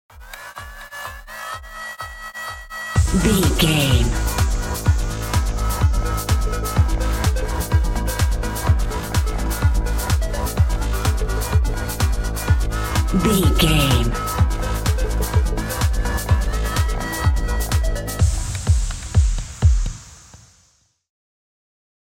Top 40 Electronic Dance Music 15 Sec.
In-crescendo
Aeolian/Minor
G#
Fast
energetic
uplifting
hypnotic
groovy
drum machine
synthesiser
house
techno
trance
synth bass
upbeat